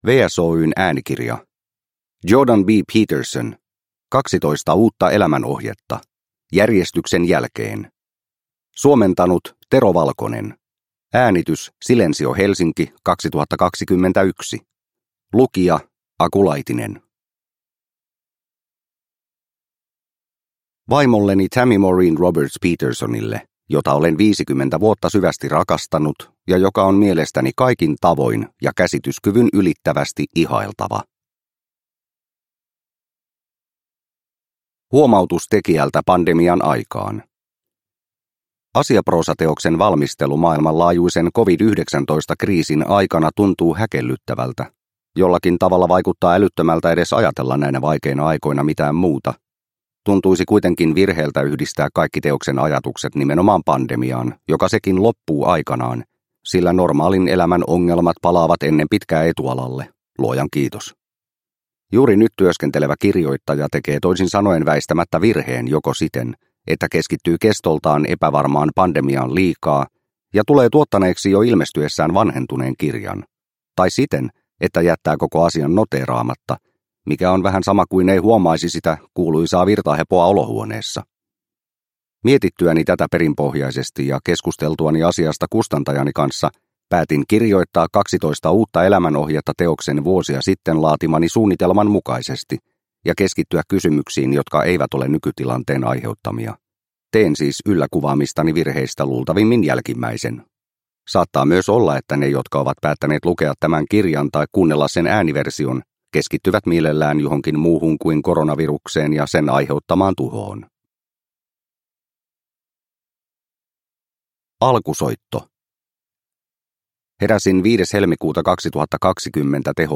12 uutta elämänohjetta – Ljudbok – Laddas ner